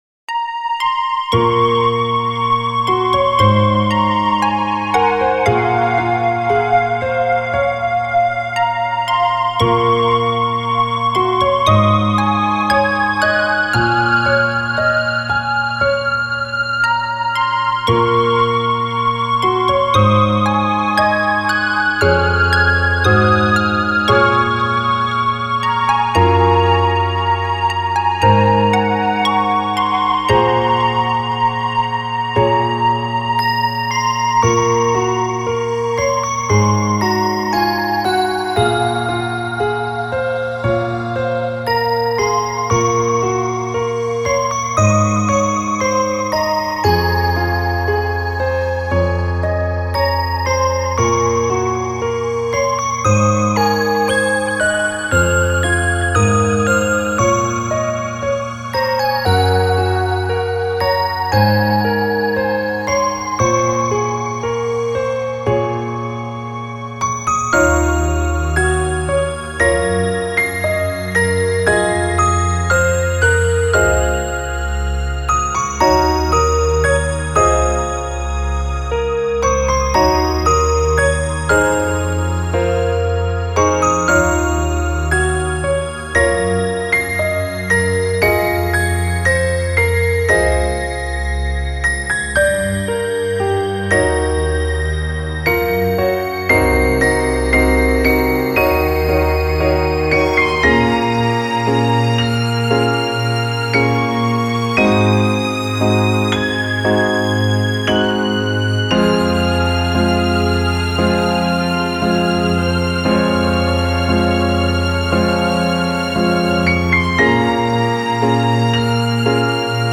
フリーBGM イベントシーン 切ない・悲しい
フェードアウト版のmp3を、こちらのページにて無料で配布しています。